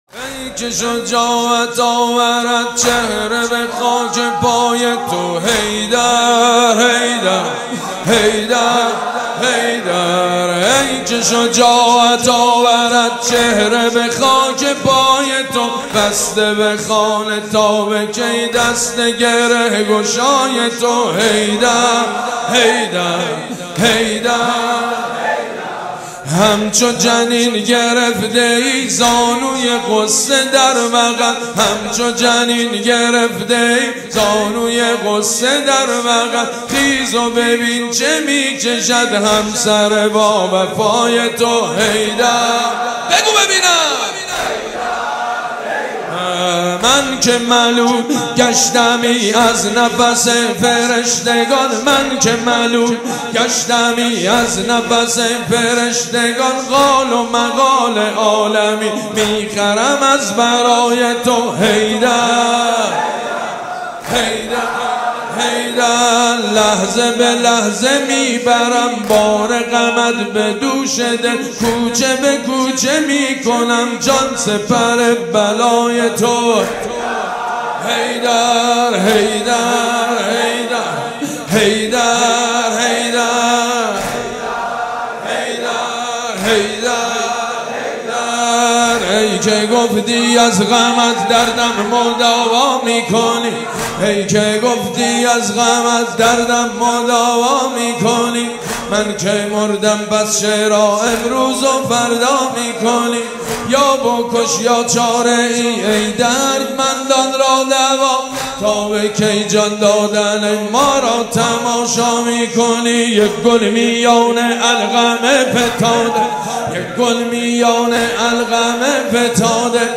ای که شجاعت آورد مجید بنی فاطمه | ایام فاطمیه 1441 | پلان 3